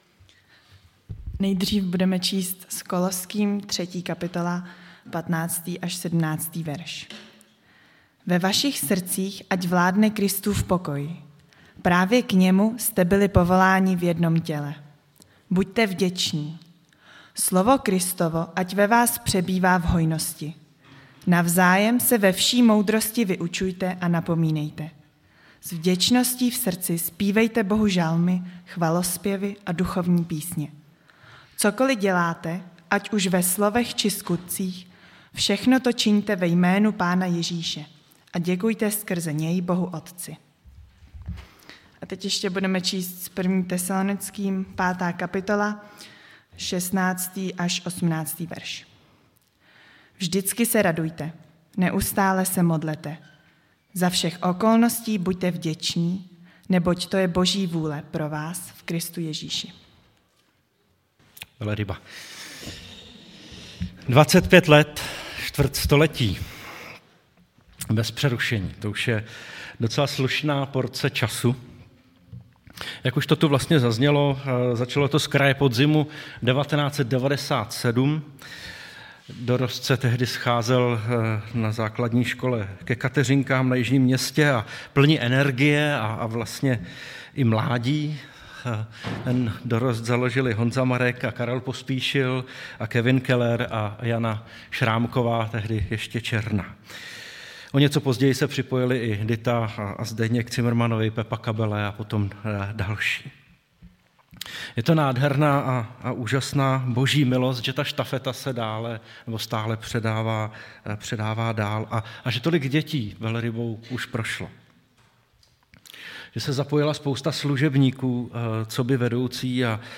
Bohoslužba: Vděčnost nejen za Velrybu
Nedělní bohoslužby přehrát